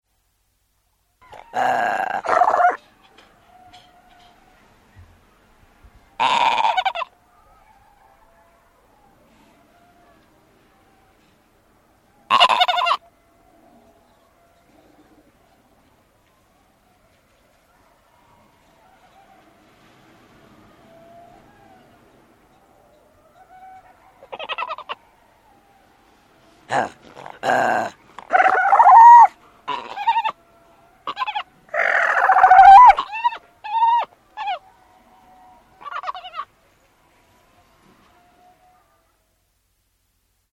Грозное рычание арктического тюленя (морского котика)